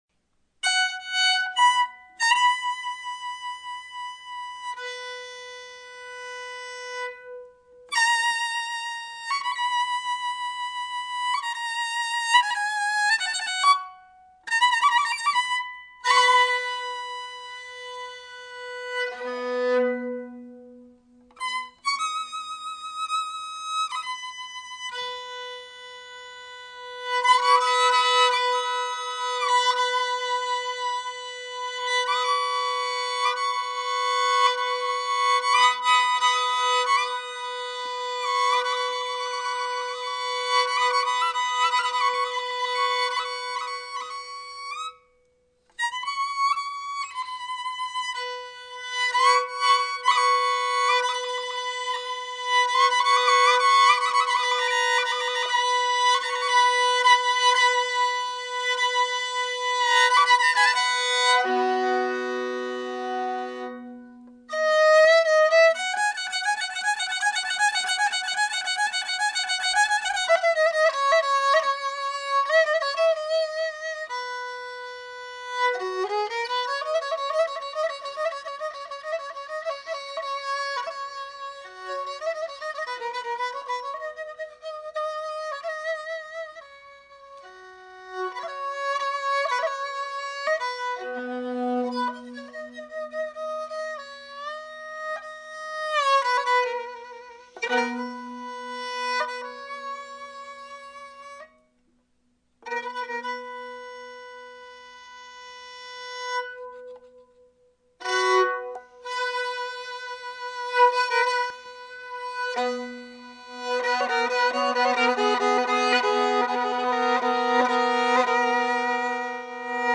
kamancha